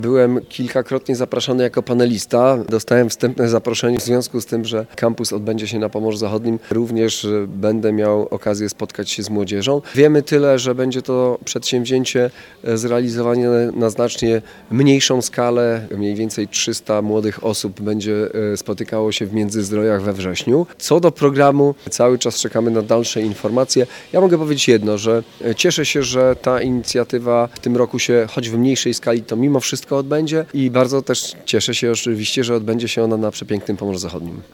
I to właśnie u nas – mówi marszałek województwa Olgierd Geblewicz.